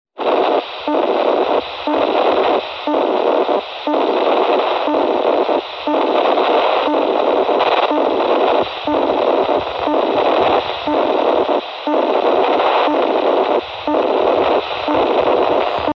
belarusian-radiosond.mp3